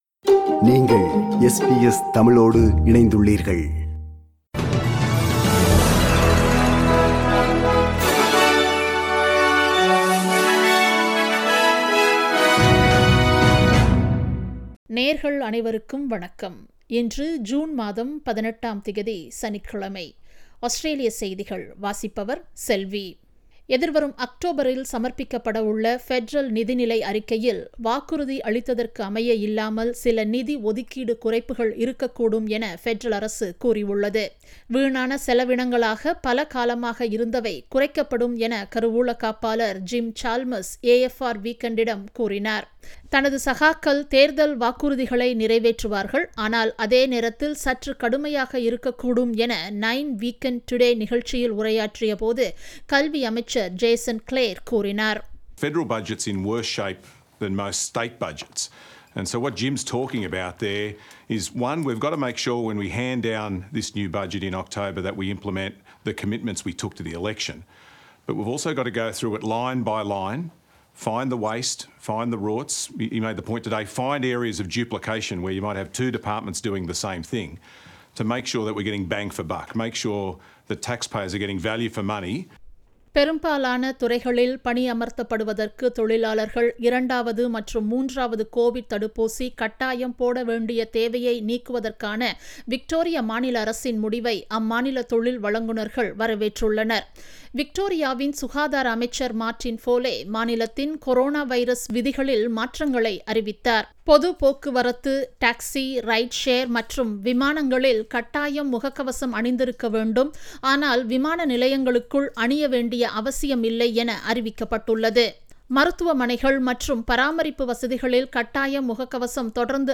Australian news bulletin for Saturday 18 June 2022.